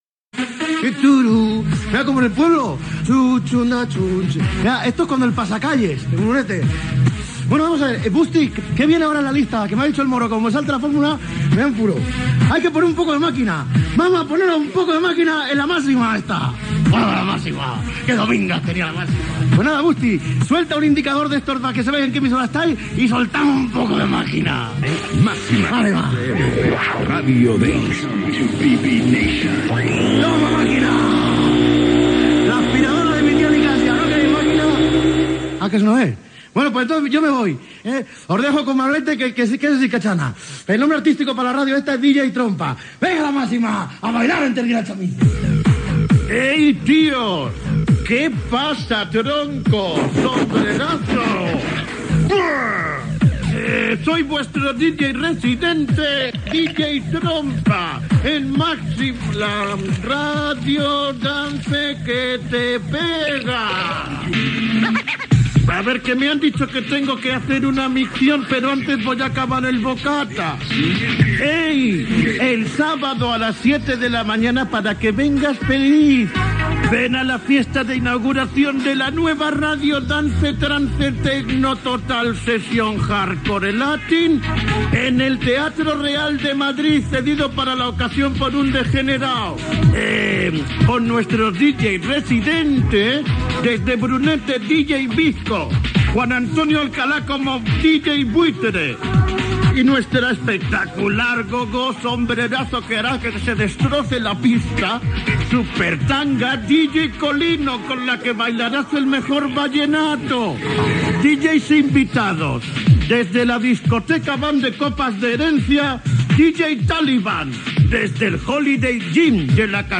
Secció "El radiador" on fan paròdia de la nova cadena Máxima FM de la Cadena SER i els seus periodistes esportius.
Esportiu
Programa presentat per José Antonio Abellán.